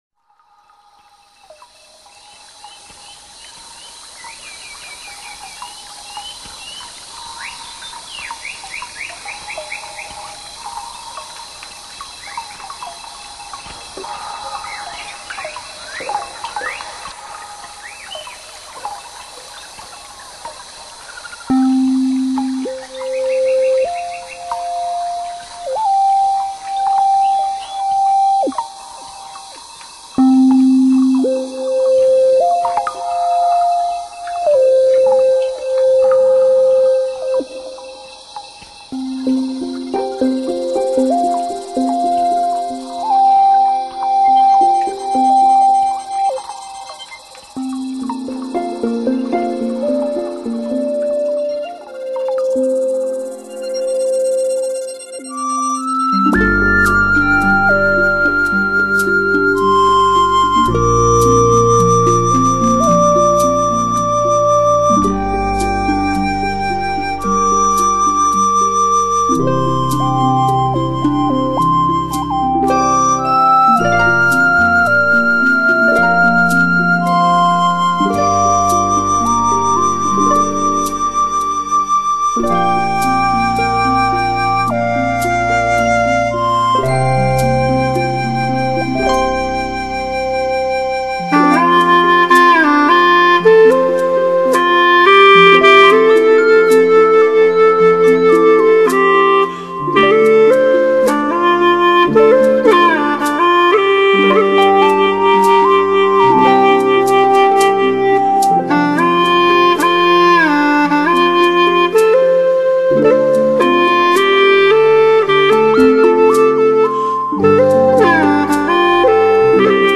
葫芦丝